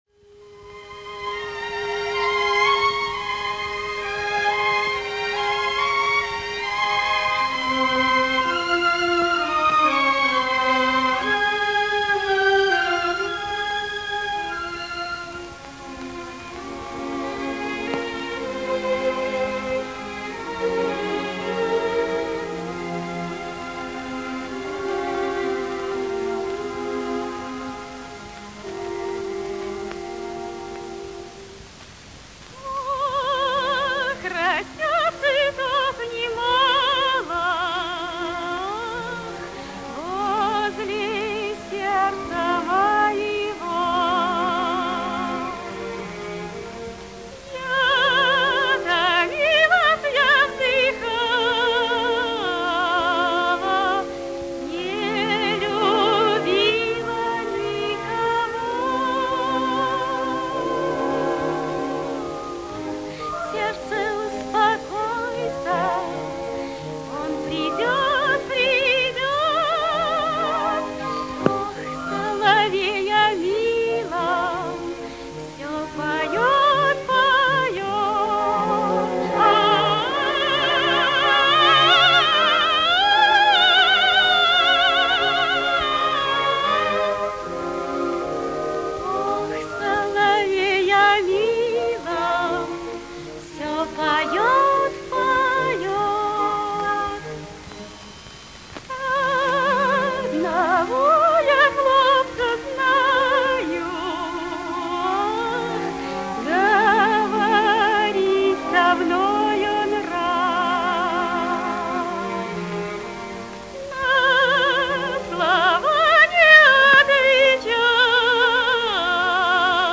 И песня чудесная, душевно очень поёт.